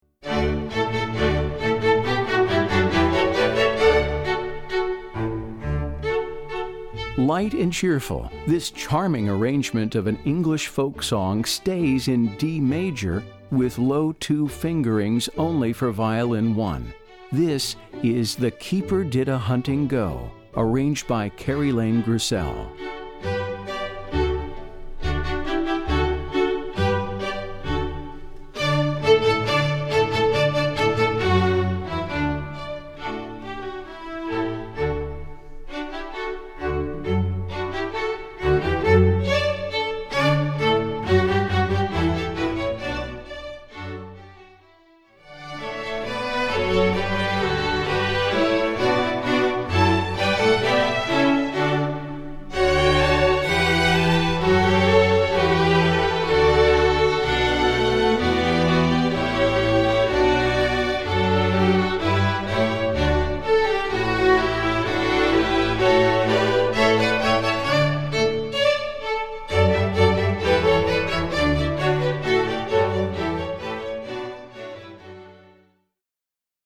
Composer: English Folk Song
Voicing: String Orchestra